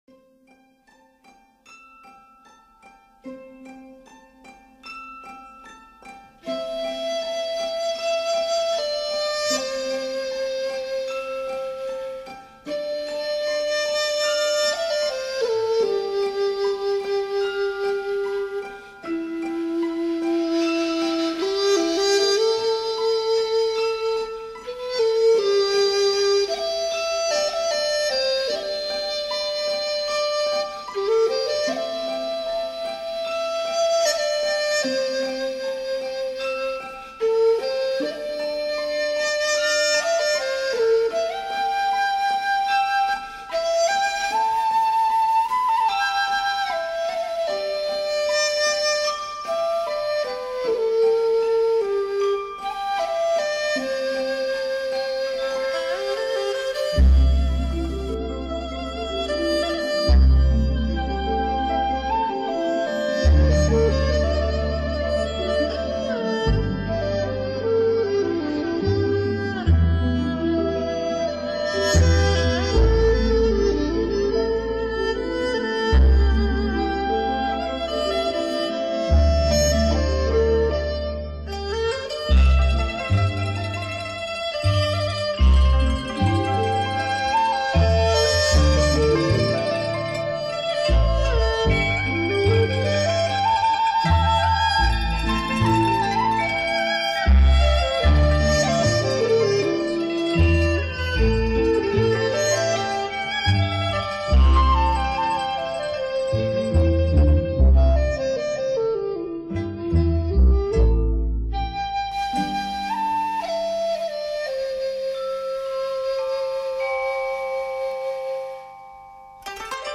笛子、古筝、二胡三重奏
梦幻的丝竹乐，加上葫芦丝、二胡、古筝等乐器
展现出竹的风雅灵气与清新
演奏出令您难以拒绝的东方新自然乐风